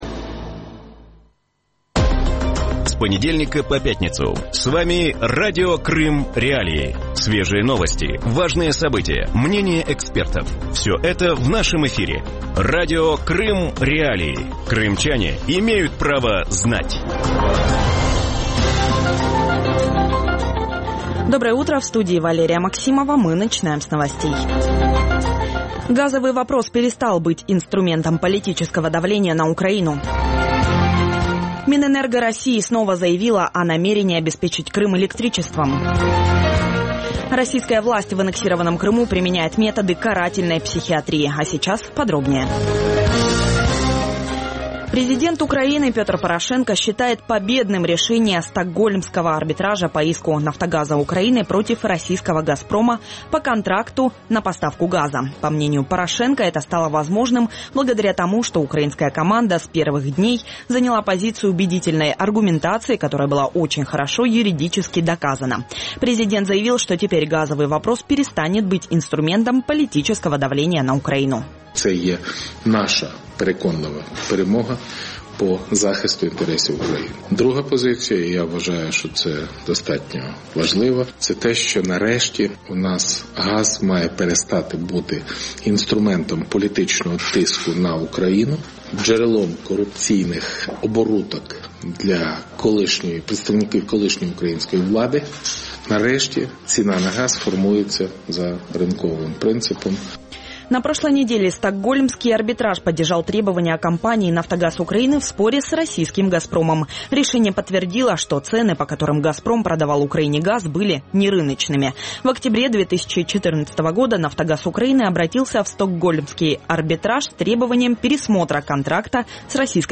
ток-шоу
експерти і журналісти обговорюють стан перинатальної медицини в Криму, можливість громадського контролю доходів російських чиновників і вороже ставлення росіян до українців.